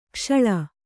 ♪ kṣaḷa